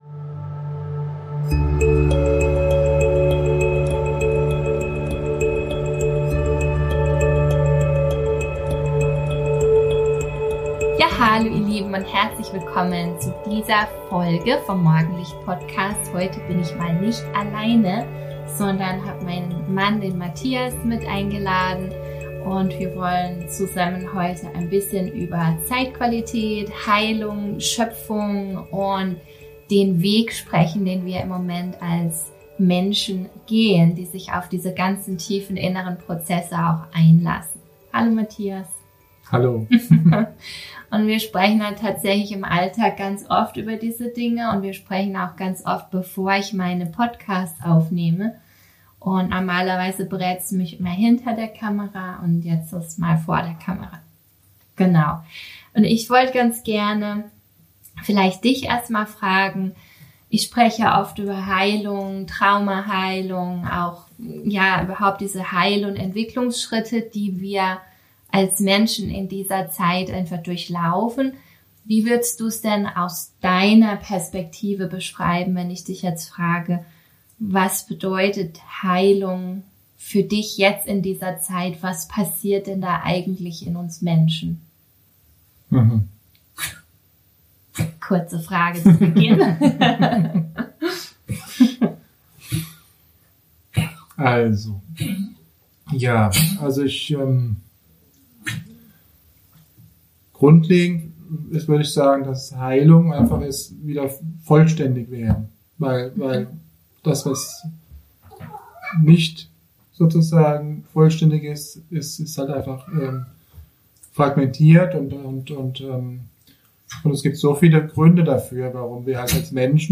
In diesem besonderen Gespräch